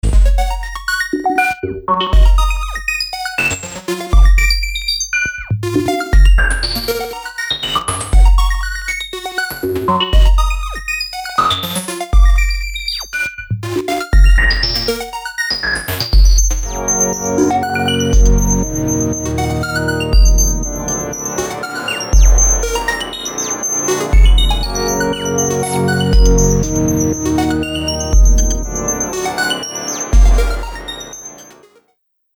громкие
Electronic
электронная музыка
спокойные
без слов